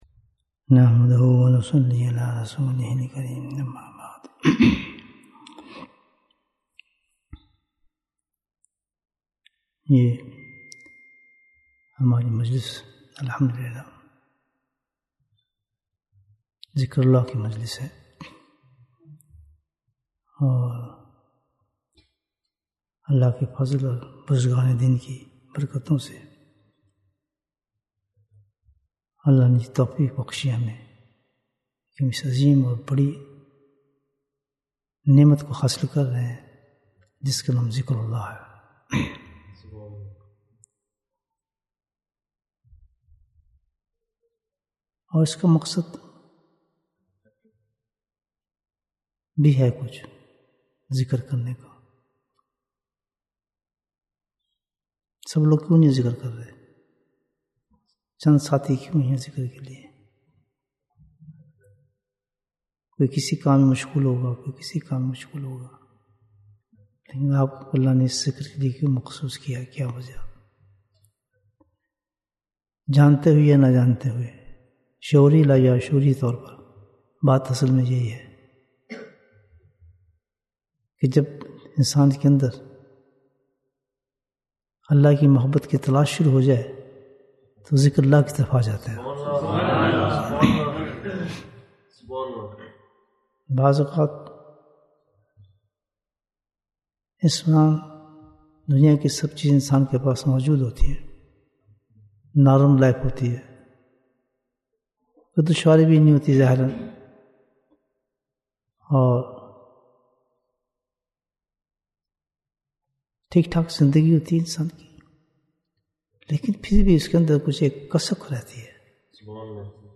Bayan, 40 minutes 8th April, 2023 Click for English Download Audio Comments What is the Base of Spirituality?